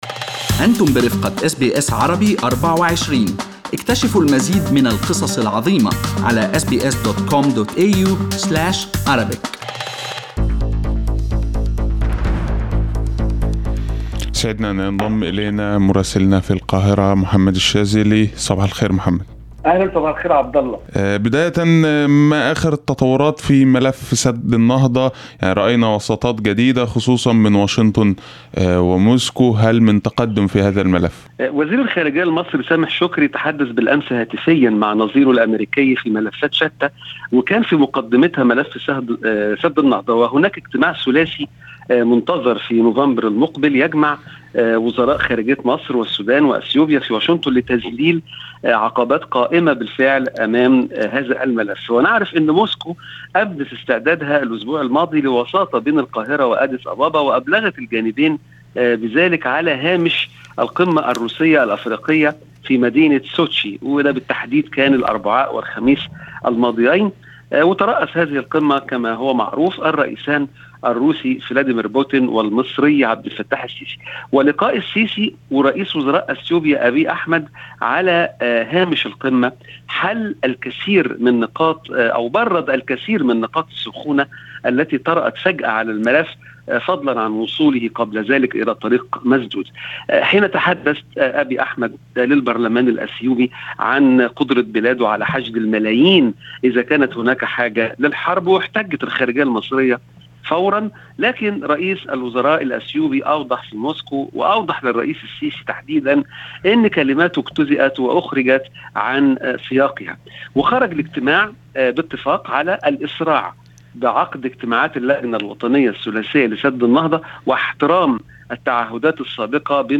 Listen to the full report from our correspondent in Egypt in Arabic above